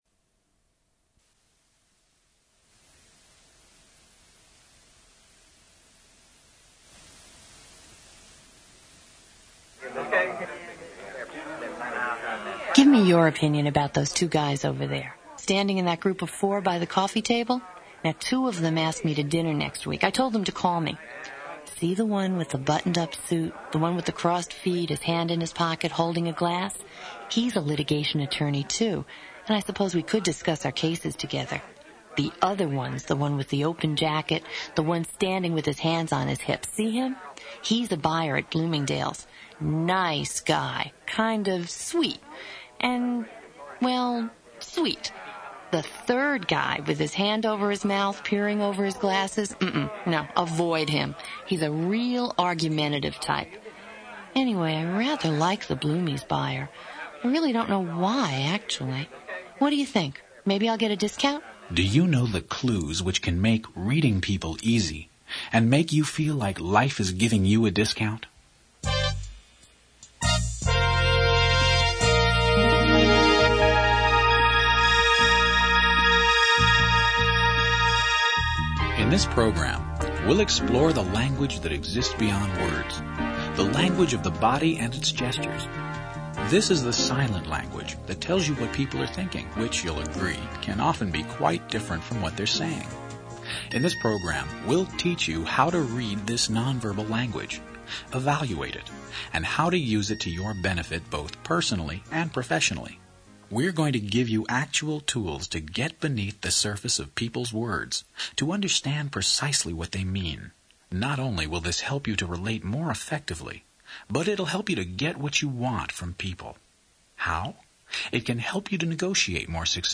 Audio_Book_-_How_To_Read_A_Person_Like_A_Book.mp3